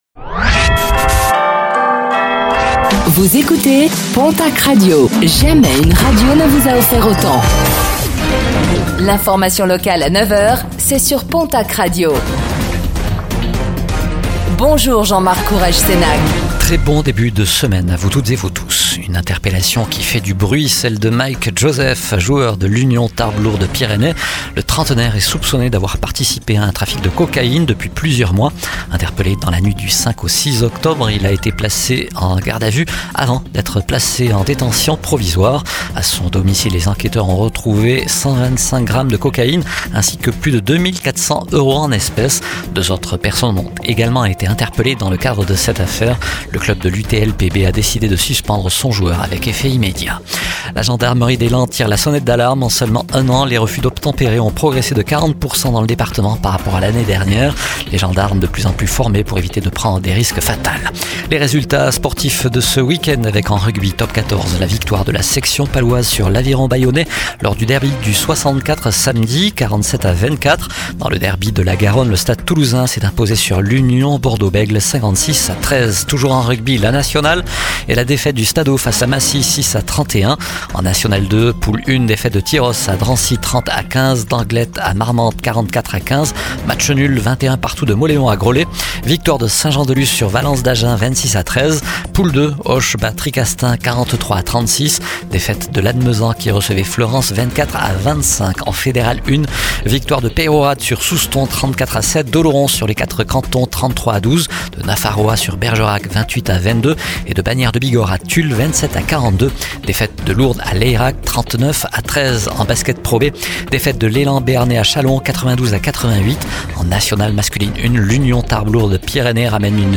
Réécoutez le flash d'information locale de ce lundi 13 octobre 2025